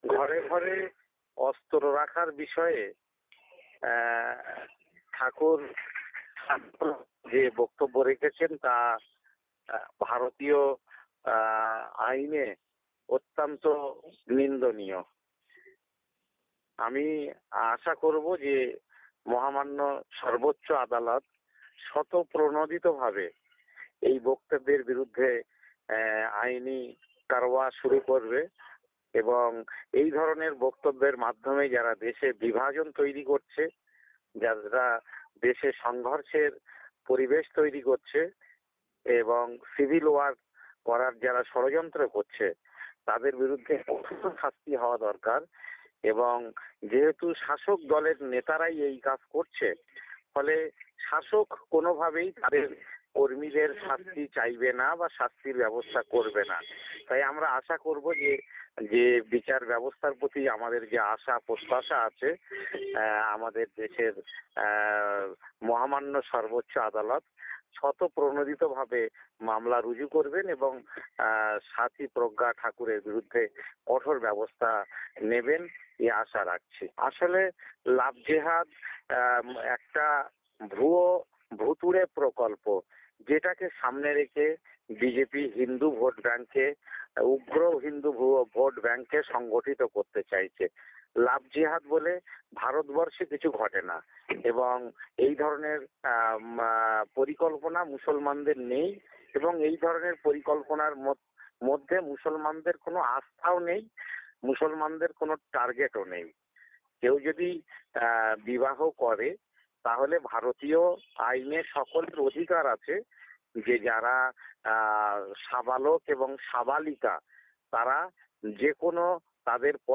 তিনি আজ (বৃহস্পতিবার) রেডিও তেহরানকে দেওয়া সাক্ষাৎকারে ওই মন্তব্য করেন।